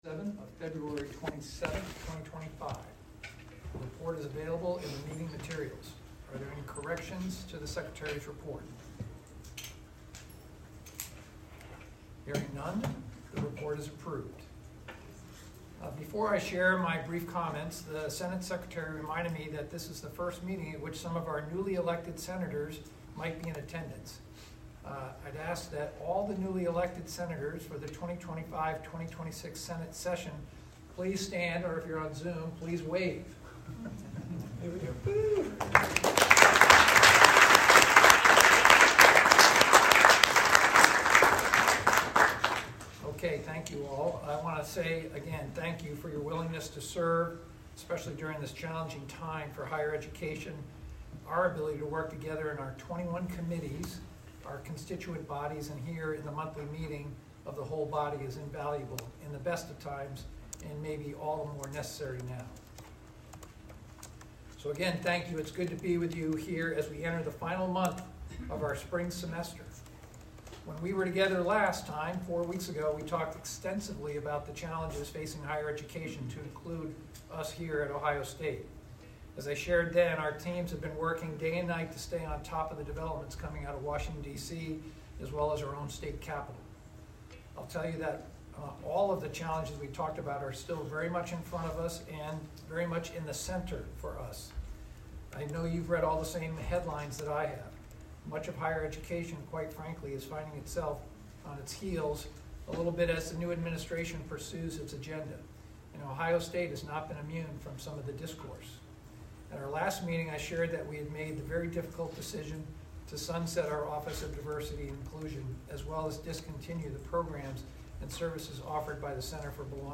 This meeting will be a hybrid meeting.
President Ted Carter will be the presiding officer.